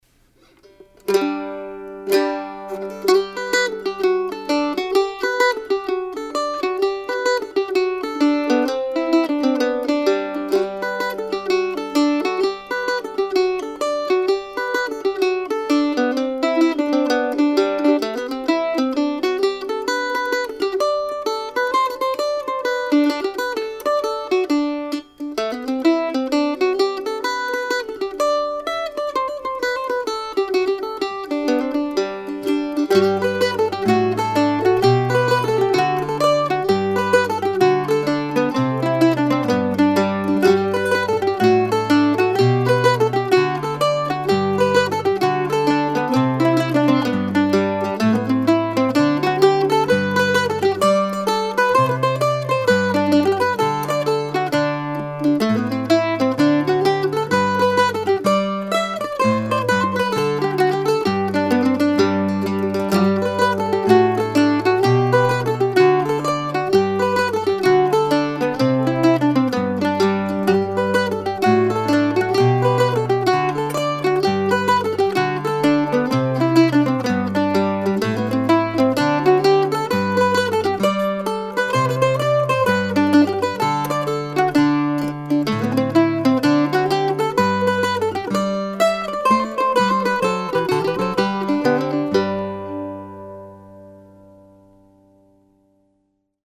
The tune is a simple jig that lays nicely under the fingers.